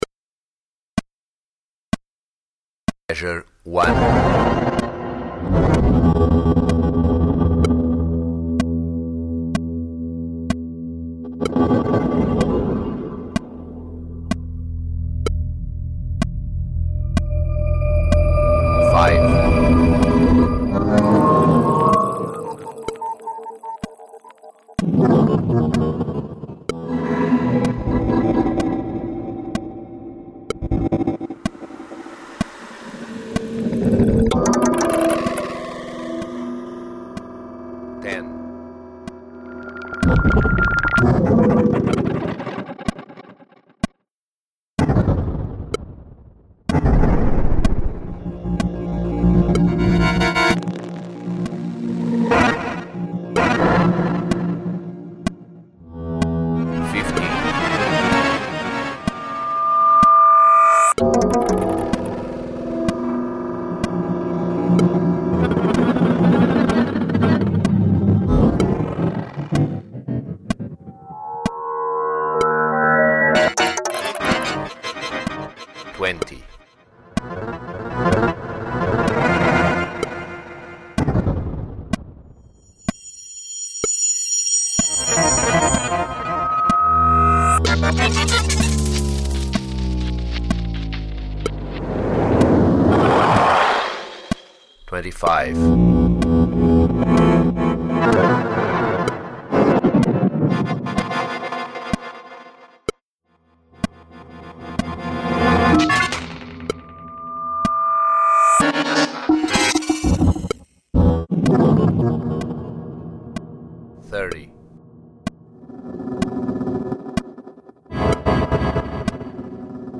Accordion Solo (with tape)